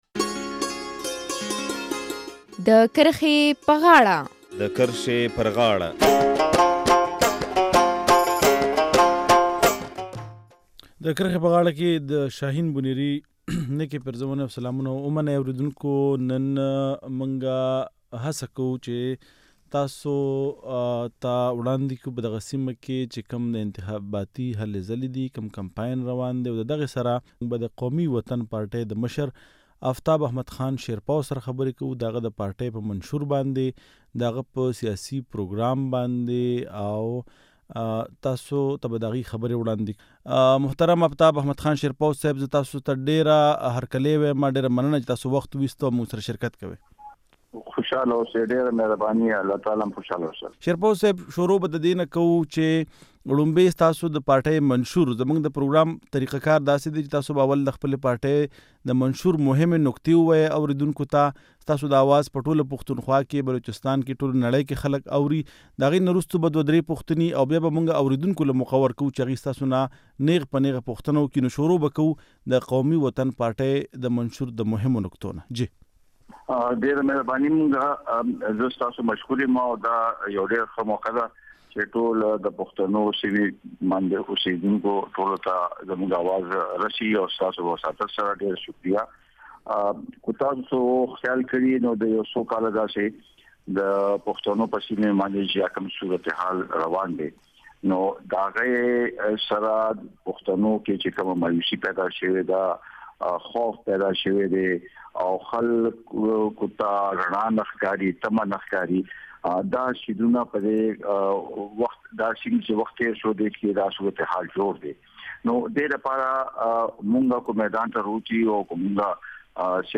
له مشر افتاب احمد خان شېر پاو سره مرکه